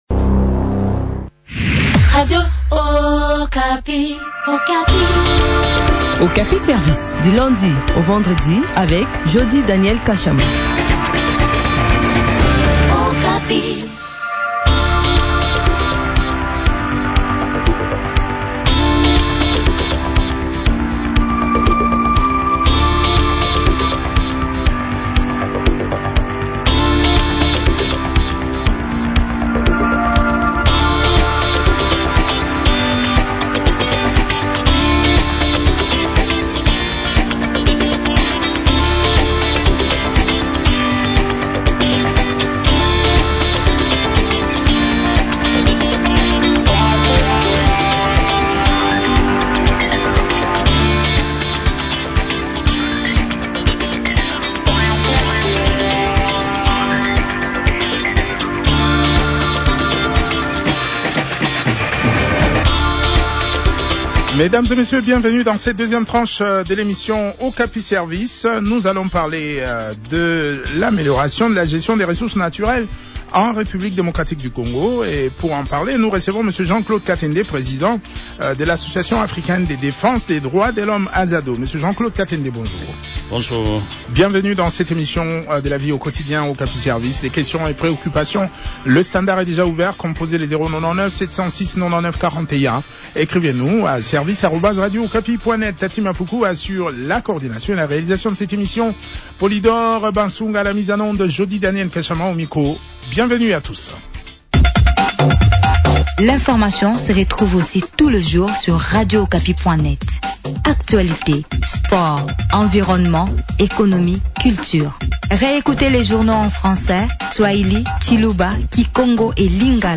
s’entretient à ce sujet avec